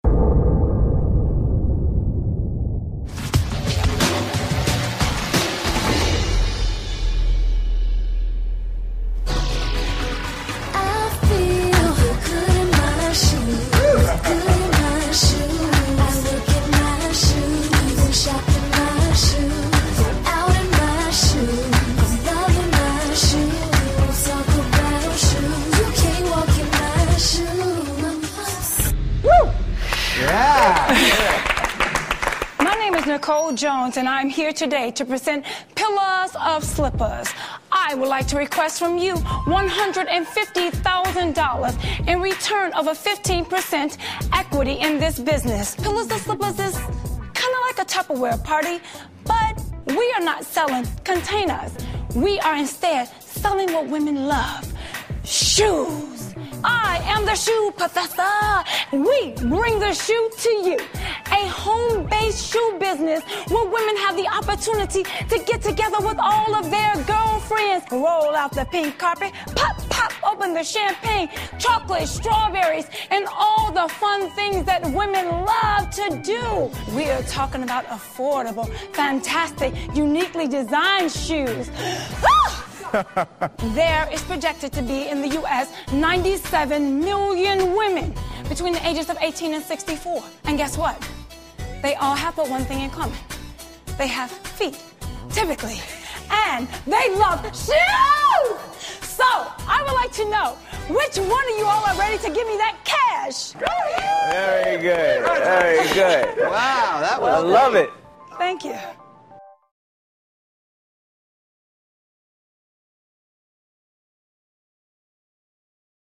访谈录 2010-02-22&2010-02-24 Shark Tank 听力文件下载—在线英语听力室